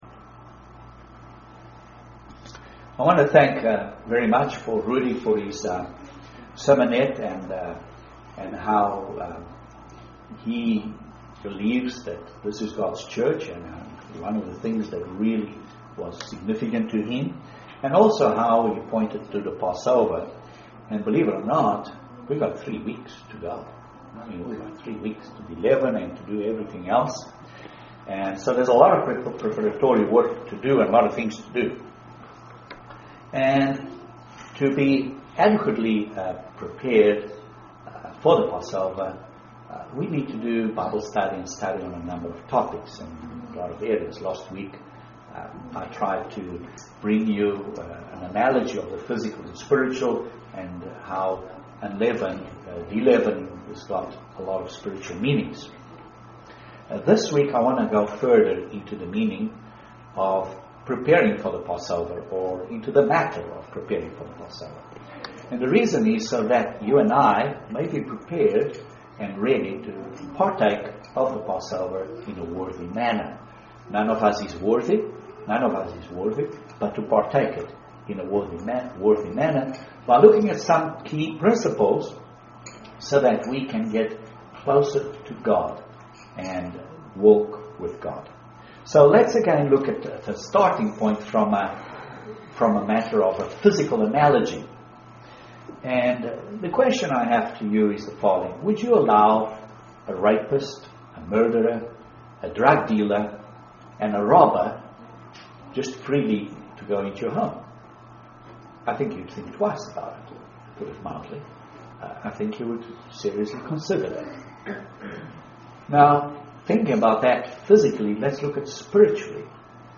Three areas to examine ourselves as we prepare for the Passover UCG Sermon Transcript This transcript was generated by AI and may contain errors.